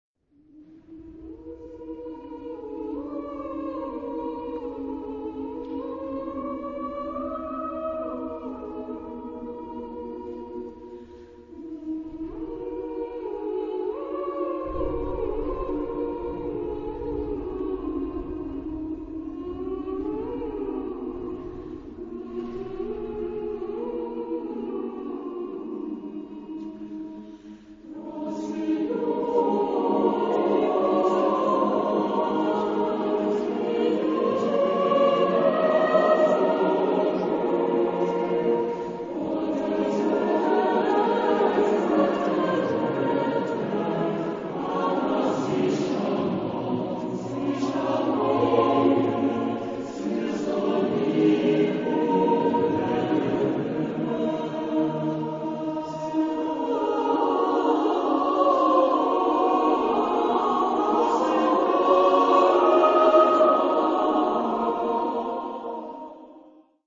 Choeur mixte d'après un chant populaire
Tonality: E dorian